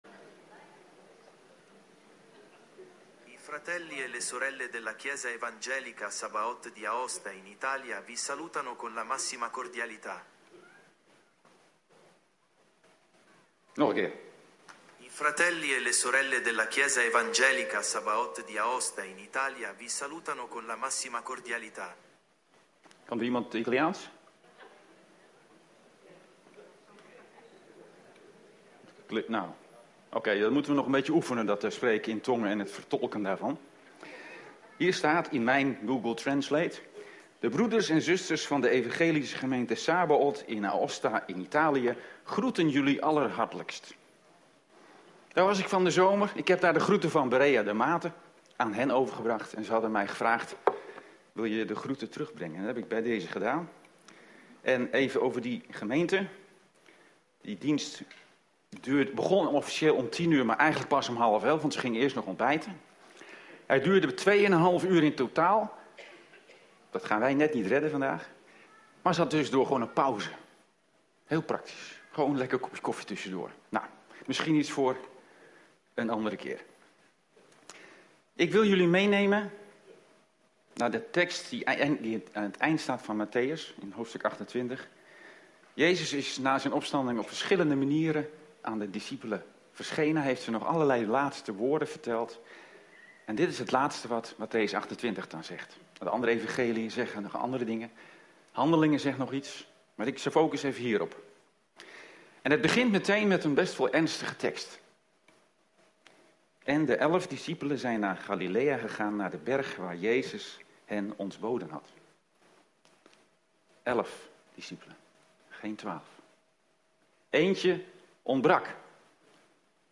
Doopdienst